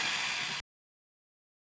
Noise FX